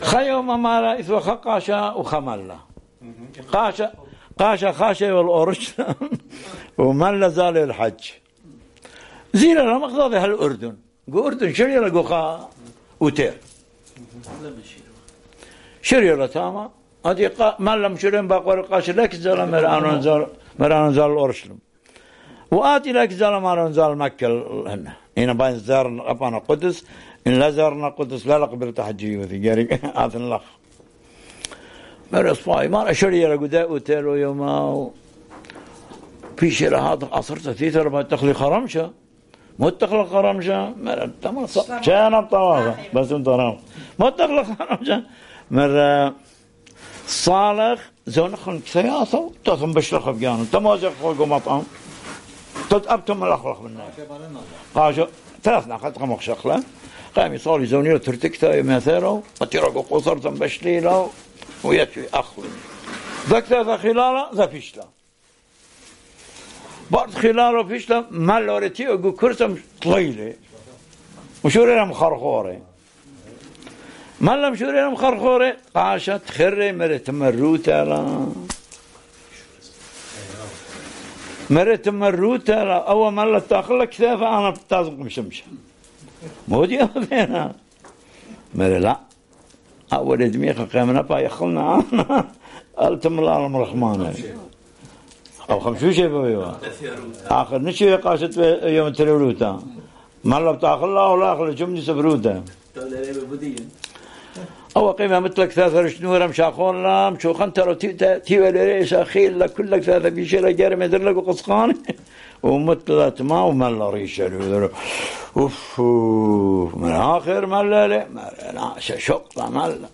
Barwar: The Priest and the Mullah